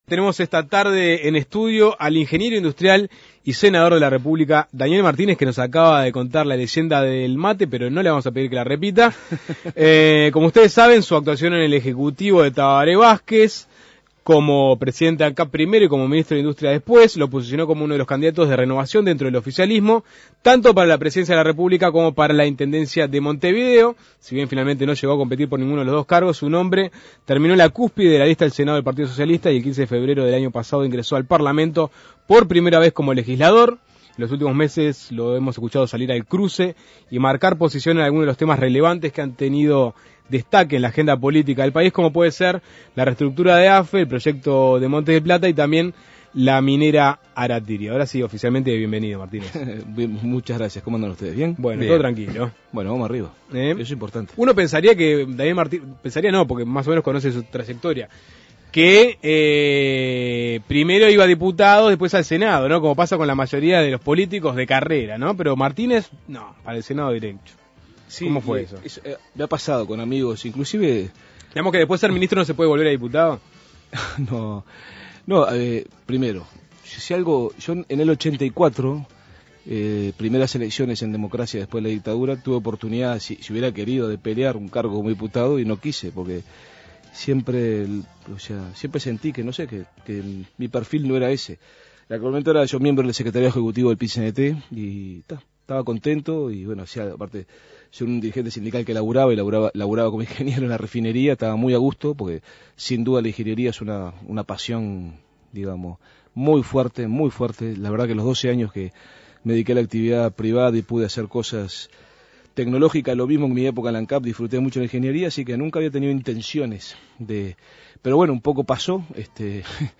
Esa visibilidad lo llevó a la cúspide de la lista al Senado por el Partido Socialista, lugar que le permitió ingresar al Parlamento como legislador, por primera vez en su trayectoria política. Suena Tremendo conversó con el ingeniero industrial y senador de la República Daniel Martínez.